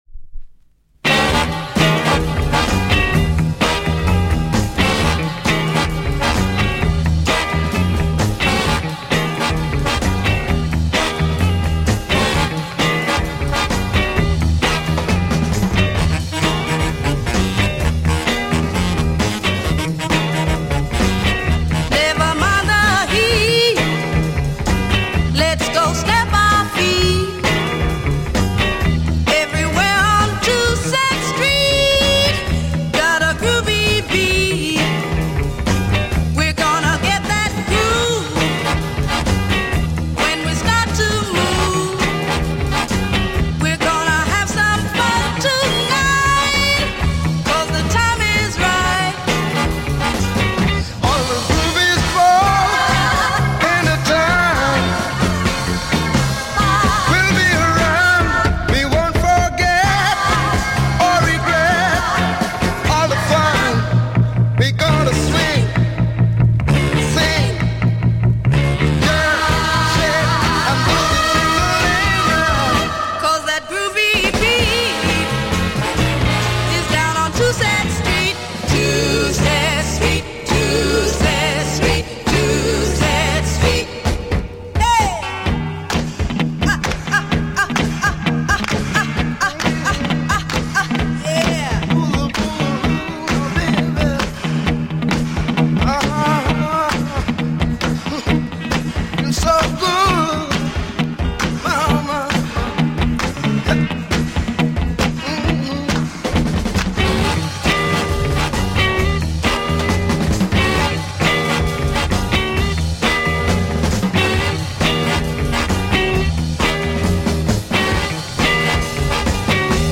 Spanish OST Killer freakbeat soul Mod